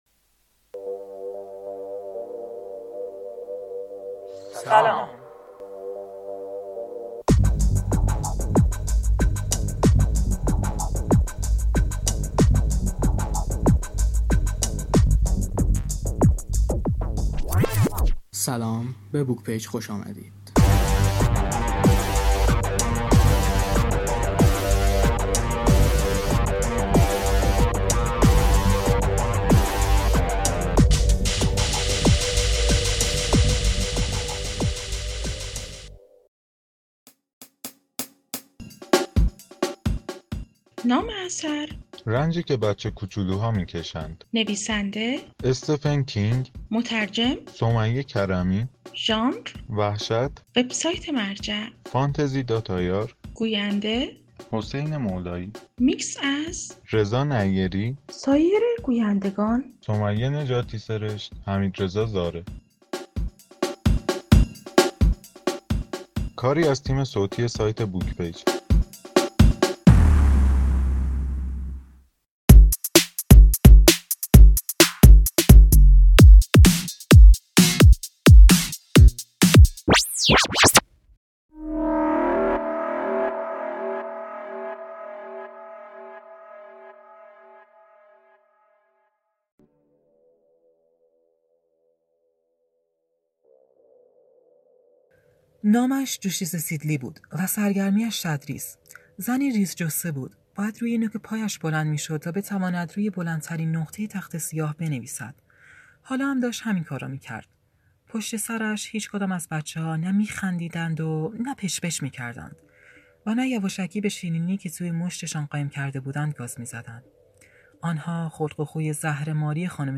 رنجی که بچه‌کوچولوها می‌کشند - داستان صوتی - پیشتازان کتاب